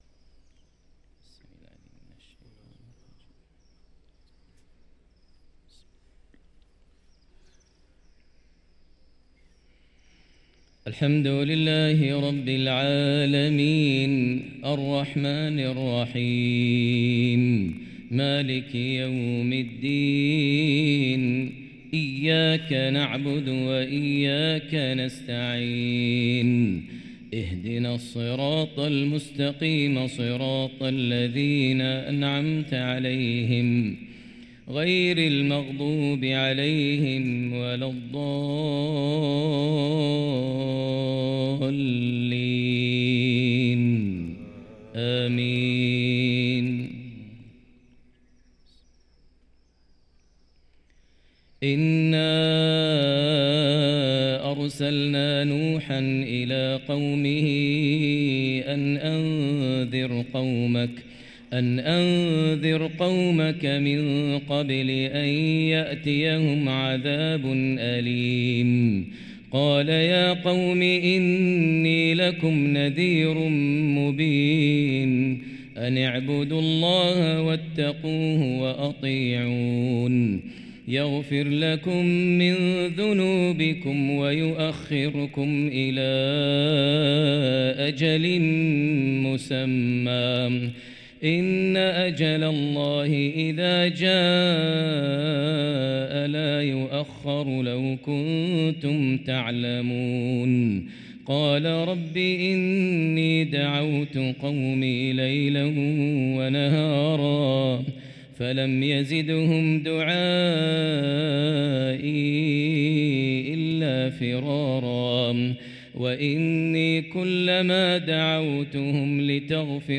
صلاة الفجر للقارئ ماهر المعيقلي 20 رجب 1445 هـ
تِلَاوَات الْحَرَمَيْن .